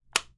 Foley " 53 Cae hueso
描述：击中一个小物体的坠落